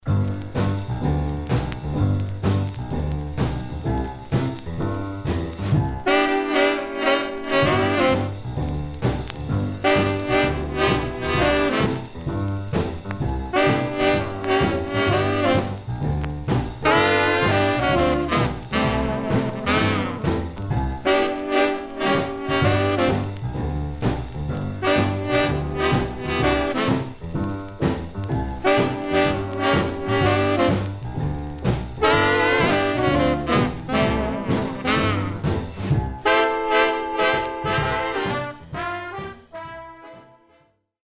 Mono
R&B Instrumental